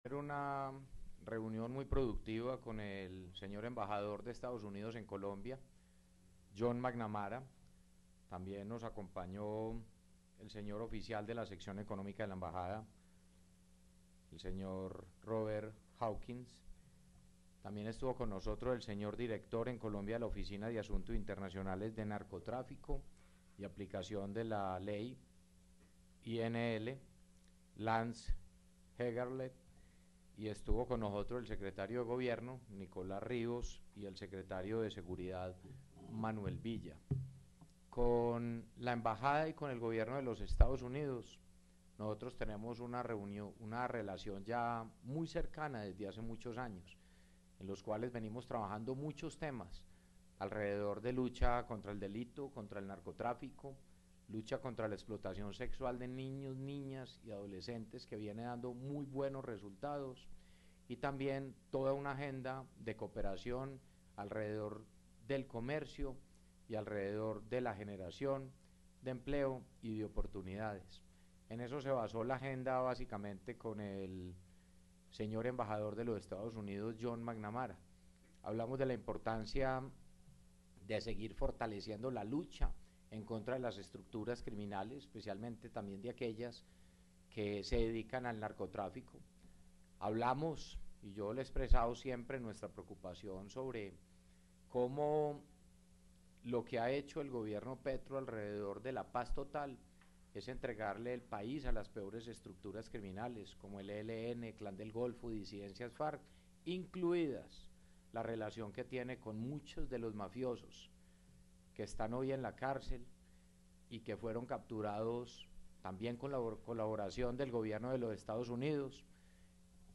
Declaraciones alcalde de Medellín, Federico Gutiérrez
Declaraciones-alcalde-de-Medellin-Federico-Gutierrez-4.mp3